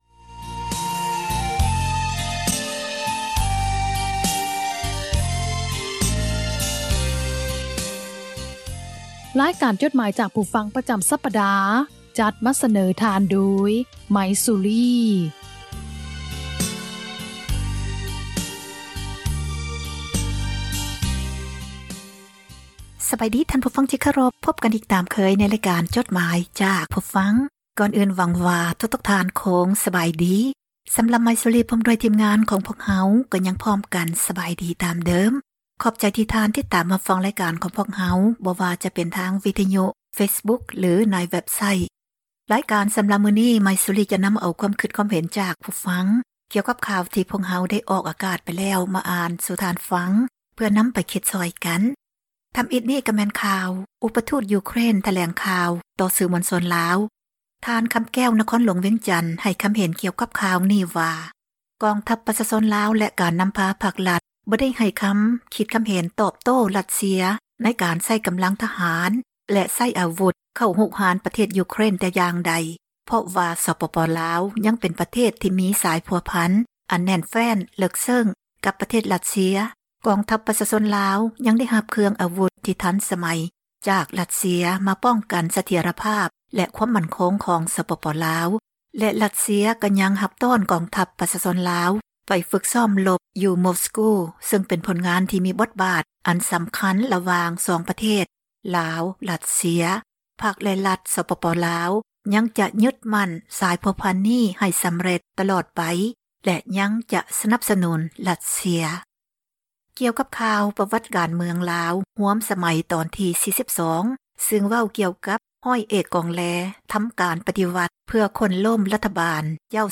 ອ່ານຈົດໝາຍ, ຄວາມຄຶດຄວາມເຫັນ ຂອງທ່ານ ສູ່ກັນຟັງ ເພື່ອເຜີຍແຜ່ ທັສນະ, ແນວຄິດ ທີ່ສ້າງສັນ, ແບ່ງປັນ ຄວາມຮູ້ ສູ່ກັນຟັງ.